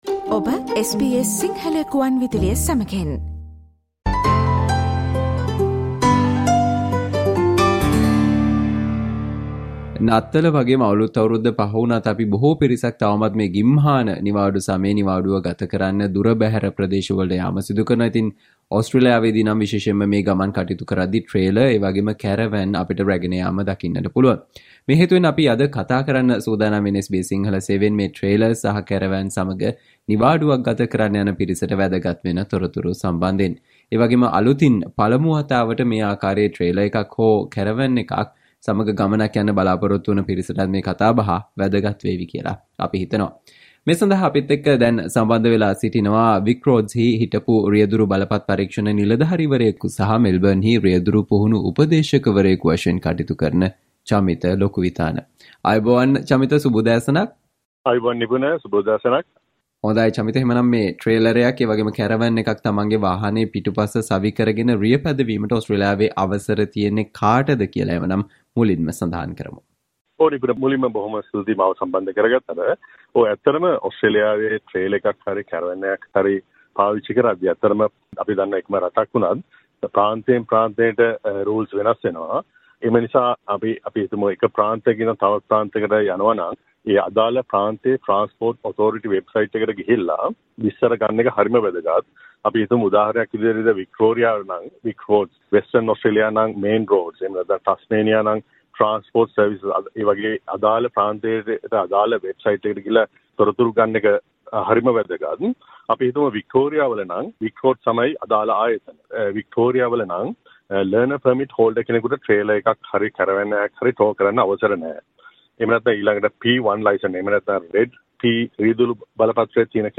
SBS Sinhala discussion on Important things you should know before heading out with a trailer or caravan this summer holiday